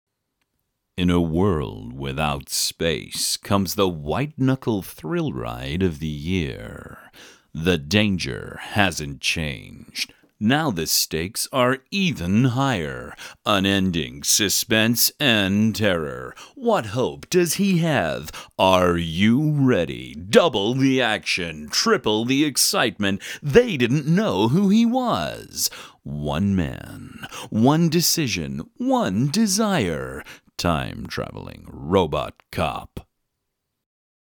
Adult
Has Own Studio
cinema trailer
smooth/sophisticated
warm/friendly